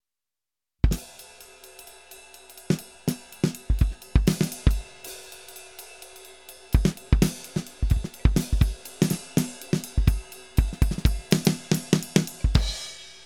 Det är bättre "bett" i cymbalen på den olimiterade, den limiterade låter inte lika "kort" i attacken utan mer utbredd i tid. Mer pshhh än ktjing.
Sen tycker jag att även den som heter "olimiterad" låter dynamikprocessad, hela ljudet "duckar" liksom lite nar baskaggen bonkar.
Trummorna kan vara processade, jag tog bara en snutt som jag hade tillgång till, jag skulle gissa på tape-kompression.
Trummor_Olimiterad_Peak-11dB_EBU_R128.wav